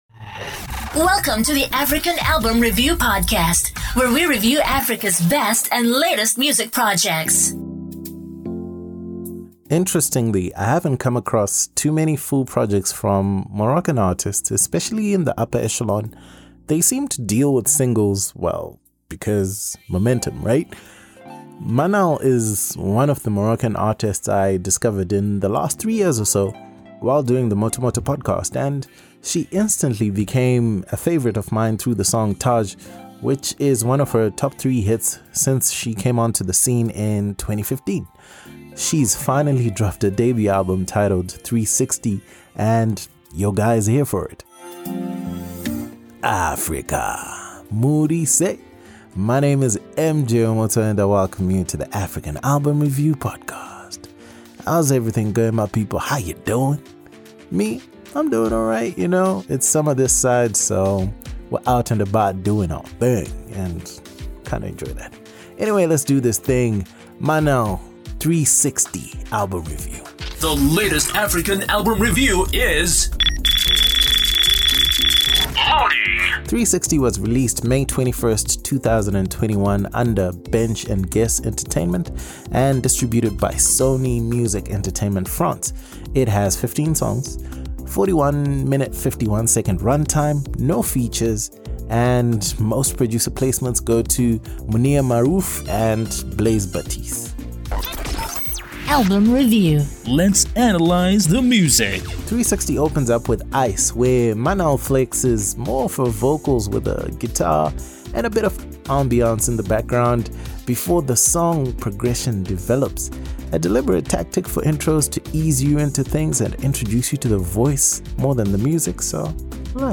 Music commentary and analysis on African albums